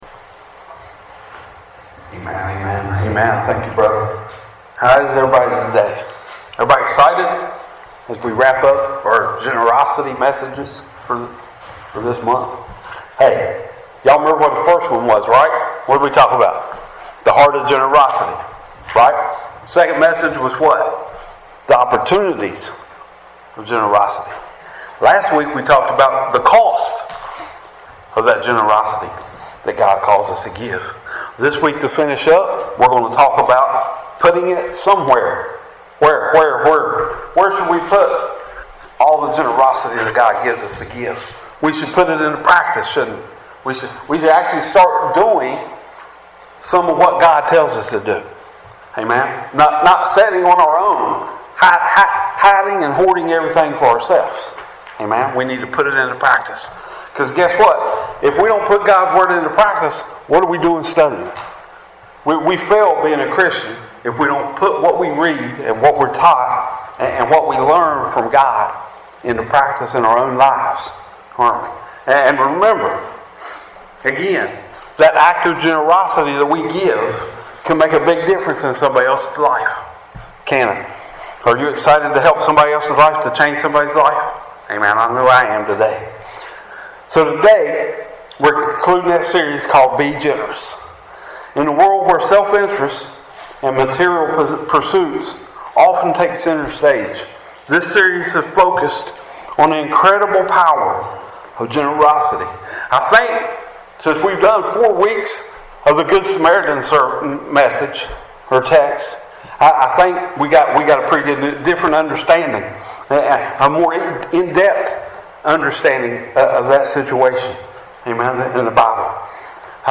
Sermons | Three Mile Wesleyan Church